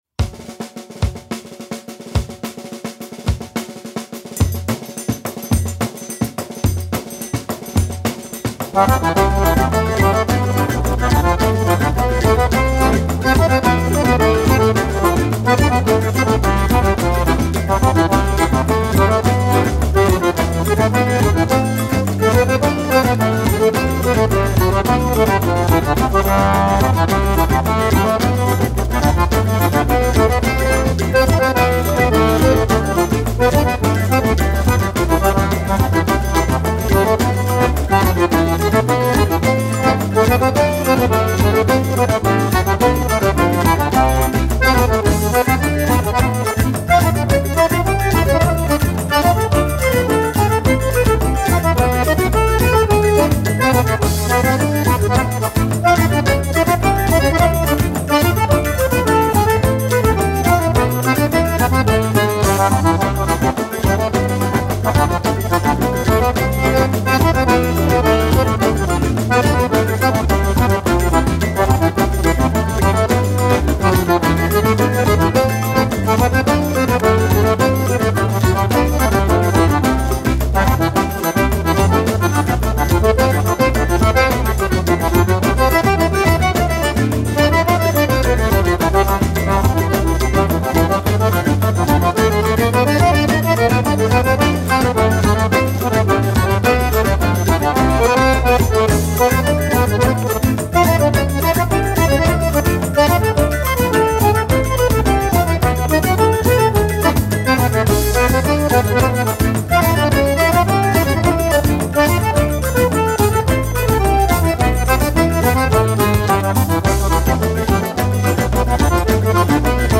1183   03:58:00   Faixa:     Xaxado